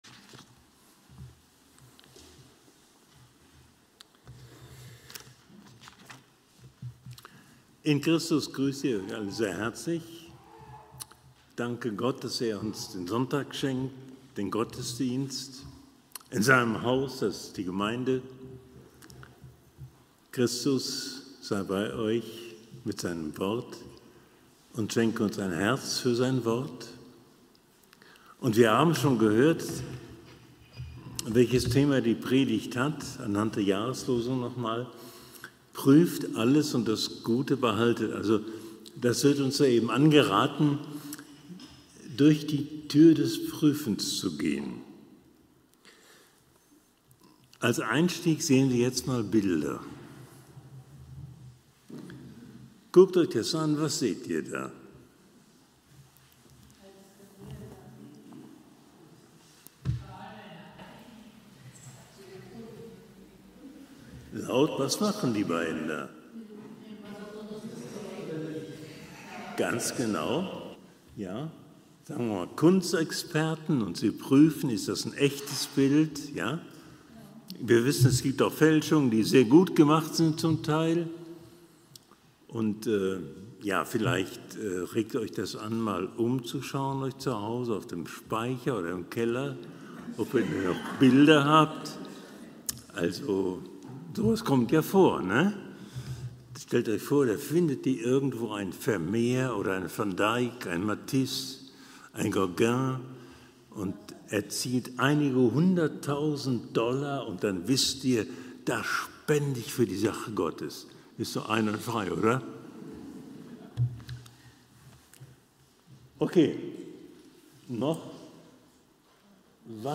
Predigt-am-10.08-online-audio-converter.com_.mp3